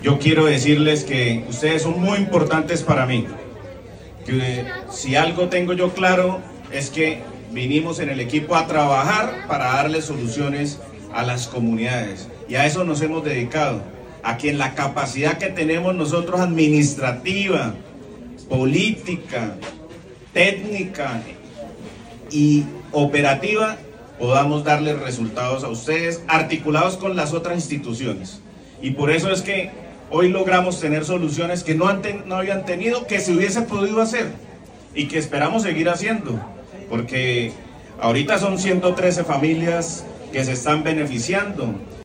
Durante el encuentro realizado en la noche de este jueves 2 de octubre con los residentes de la Ciudadela, el alcalde Campo Elías expresó: “Ustedes son muy importantes para mí.
Alcalde Campo Elías .mp3